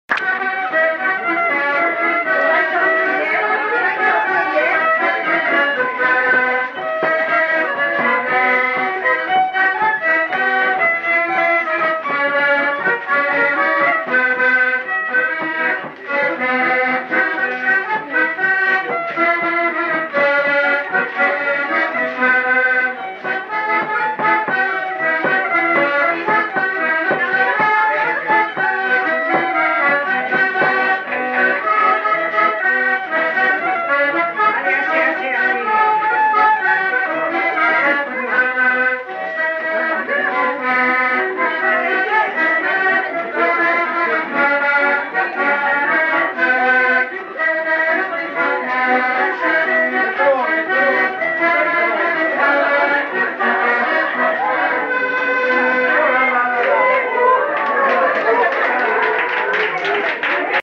Aire culturelle : Petites-Landes
Lieu : Retjons
Genre : morceau instrumental
Instrument de musique : violon ; accordéon diatonique
Danse : scottish